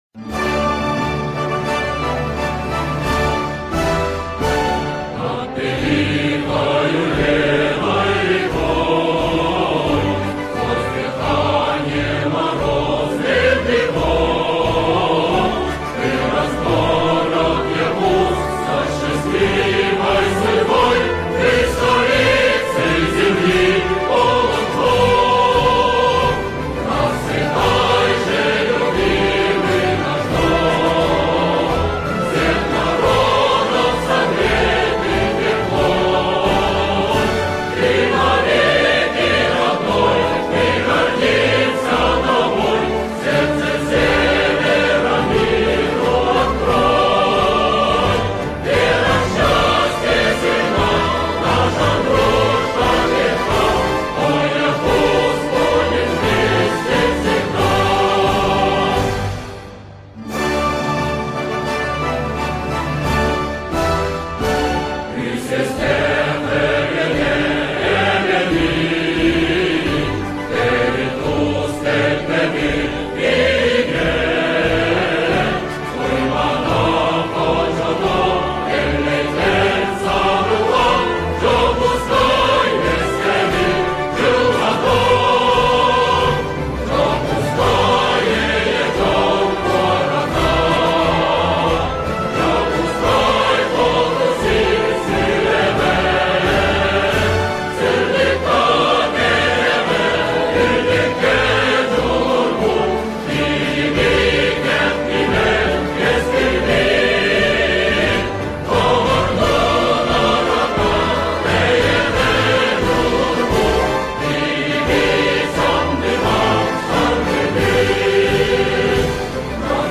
хоровое исполнение